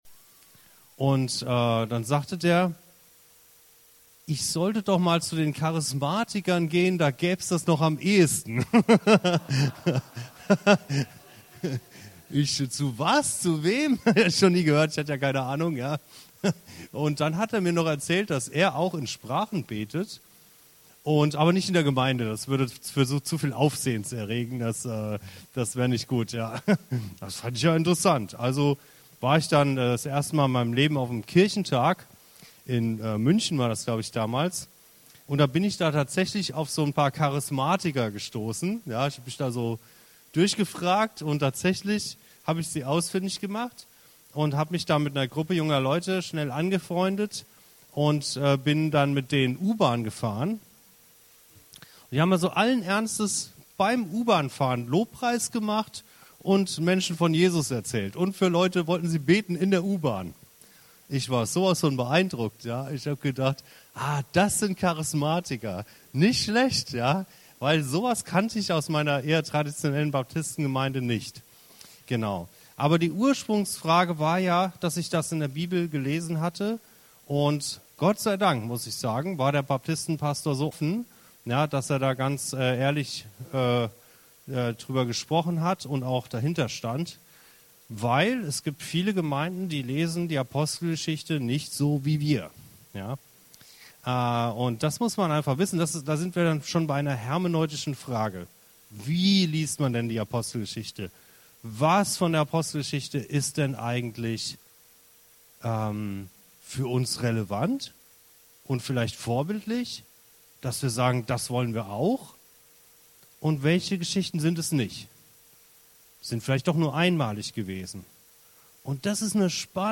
Bibelseminar